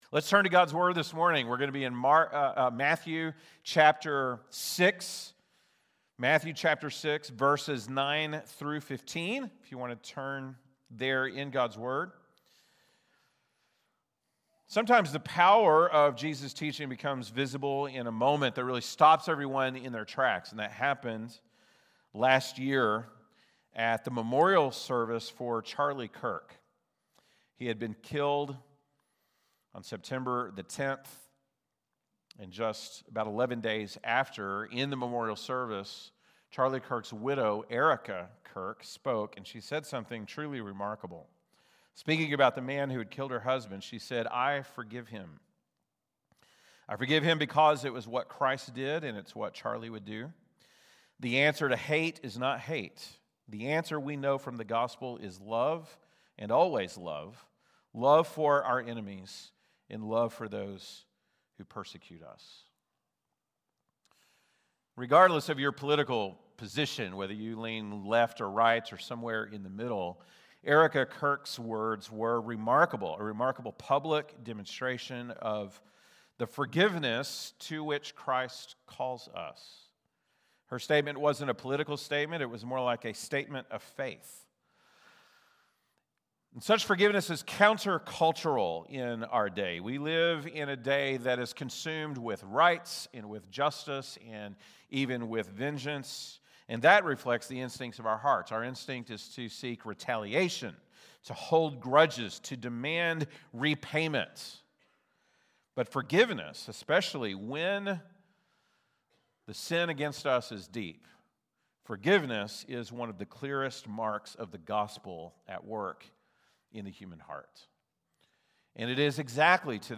March 15, 2026 (Sunday Morning)